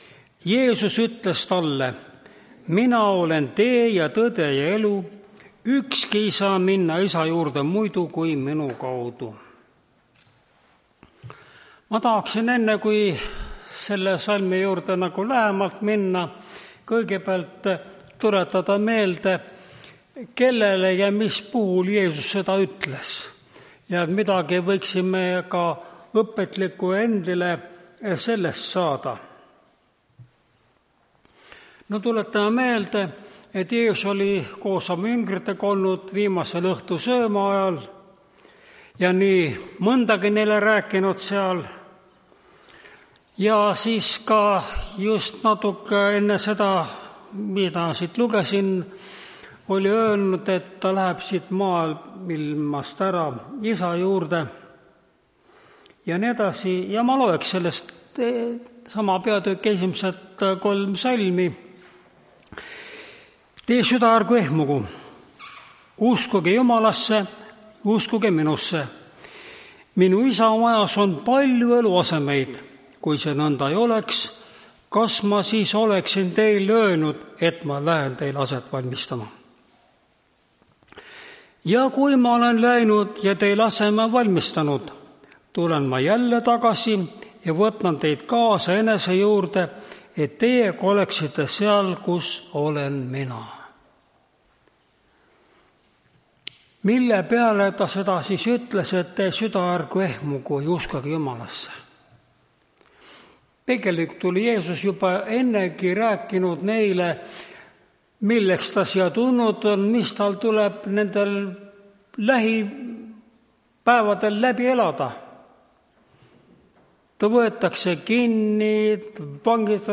Tartu adventkoguduse 12.07.2025 hommikuse teenistuse jutluse helisalvestis.